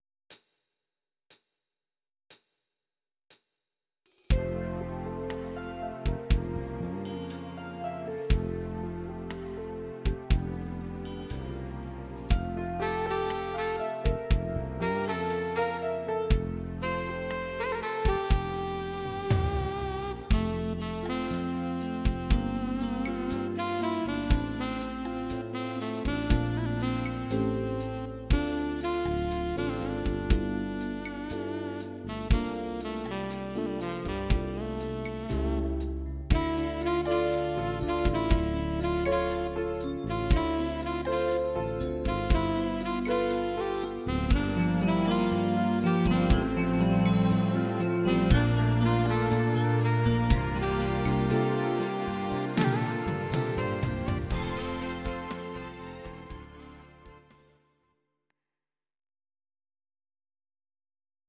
Audio Recordings based on Midi-files
Pop, 1990s